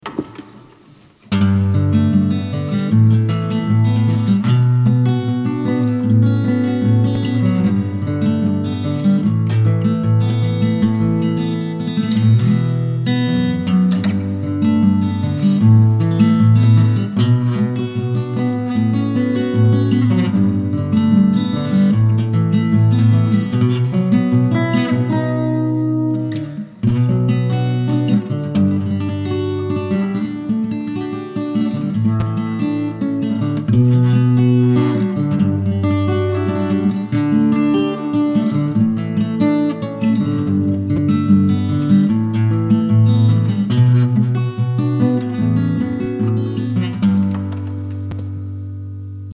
Voici un samples de ma Cort en double tracking + reverb :